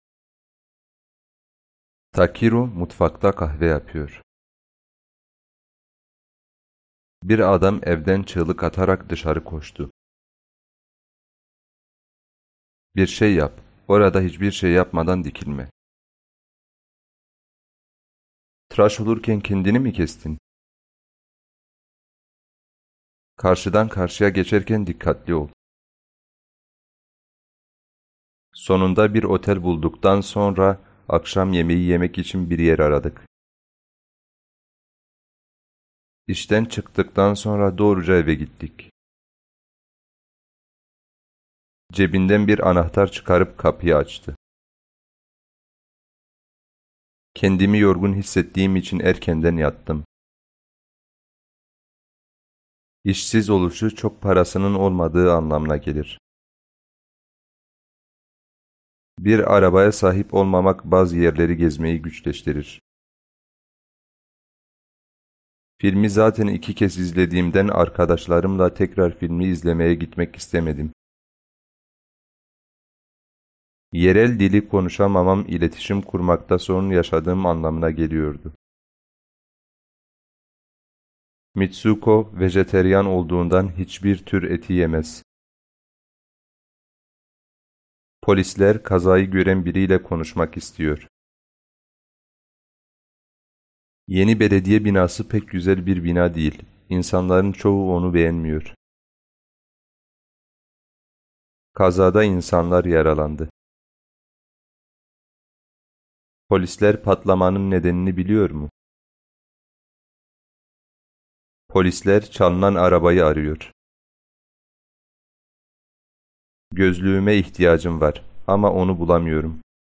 Меня всё еще интересует, чтобы вы на слух, даже там, где какие-то не очень знакомые слова есть, могли понимать дяденьку нашего.